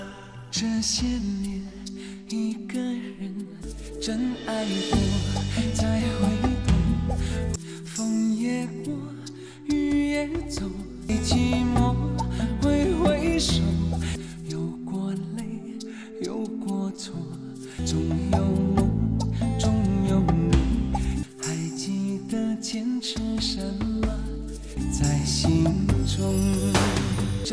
为了对比, 我们做了调整, 歌词先后顺序变成:
能不能感觉得出来, A段的语气比较轻一点, 弱一点, B 段就会重一点了.